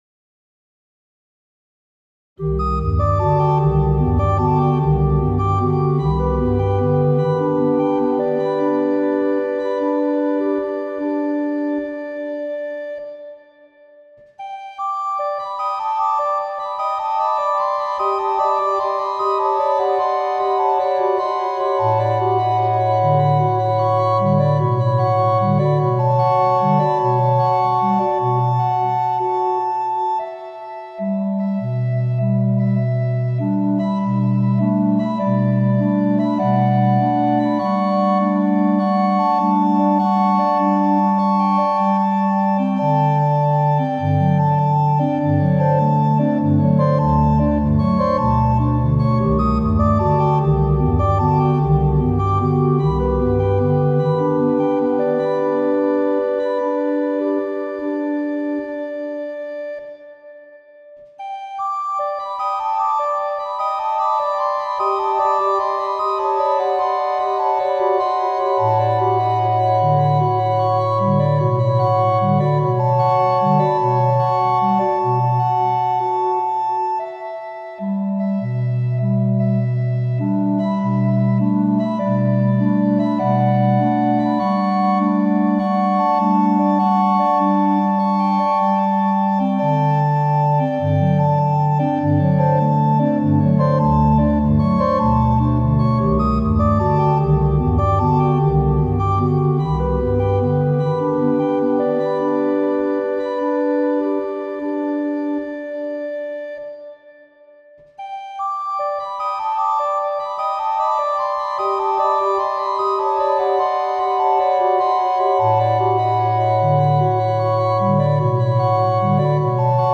• Sonification by organ (physical modeling)